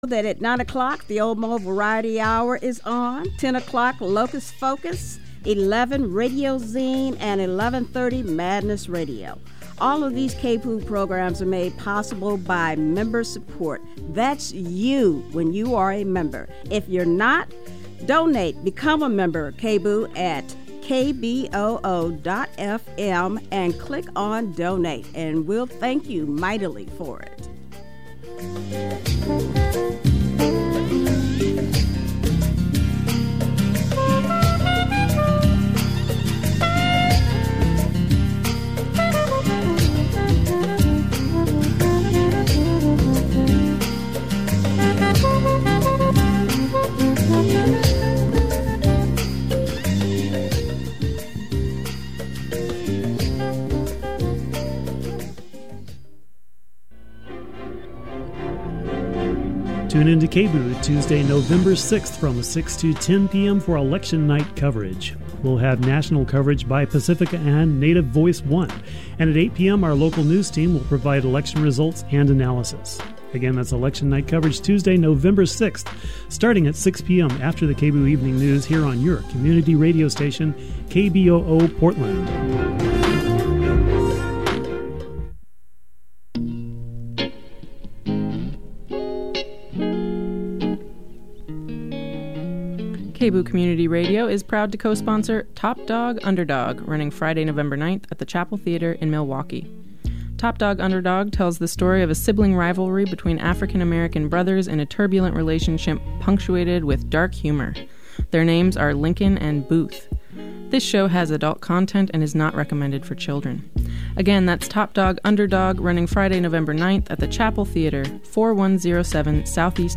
For the whole show as broadcast, use the download link or the play button below.